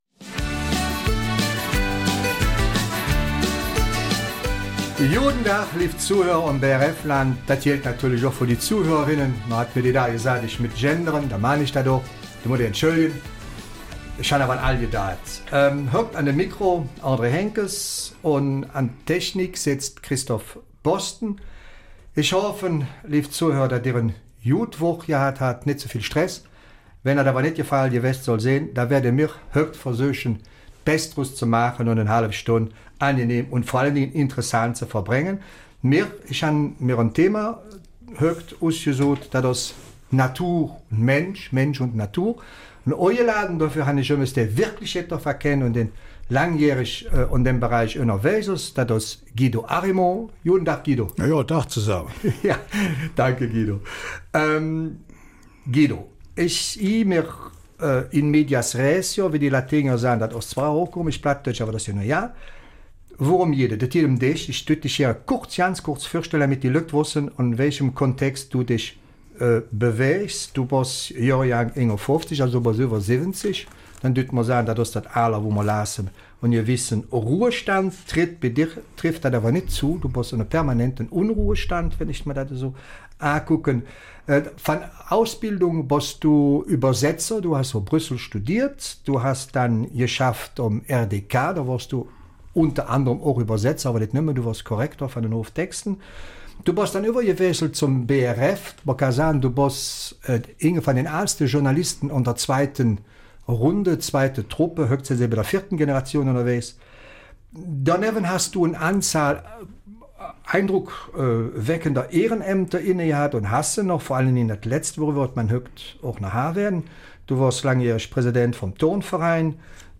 Eifeler Mundart: Mensch und Natur - eine Lebensgemeinschaft